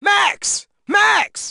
Root > content > SFX & Announcers > DDR Extreme SFX